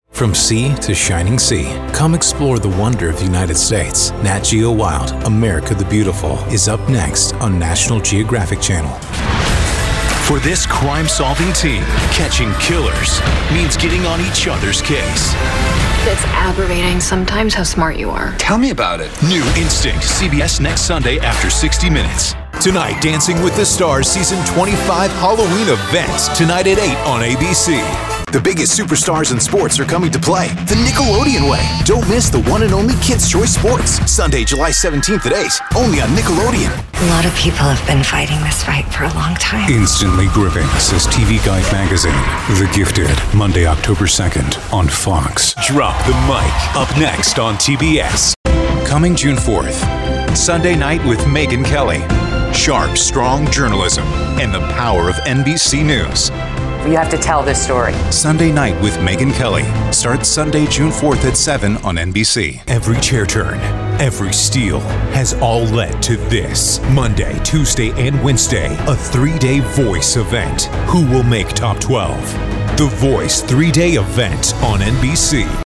new york : voiceover : commercial : men